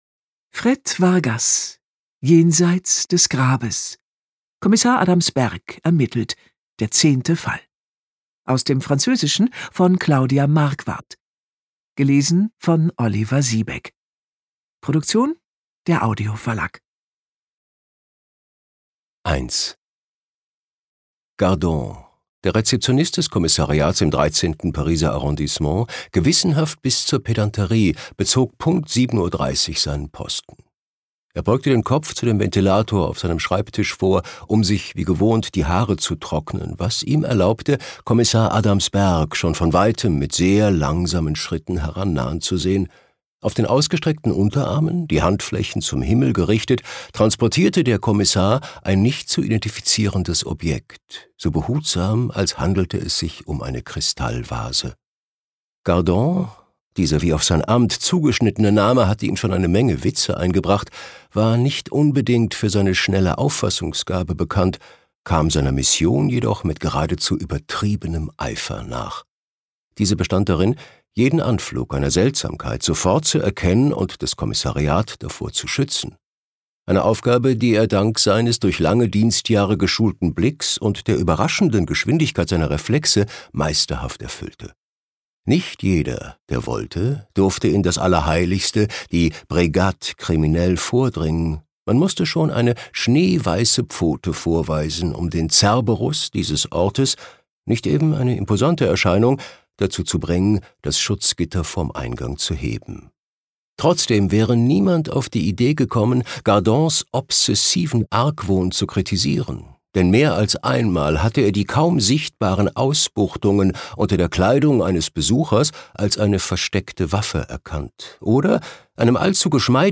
Dank seiner dunklen, prägnanten Stimme ist er außerdem ein beliebter Hörbuchsprecher.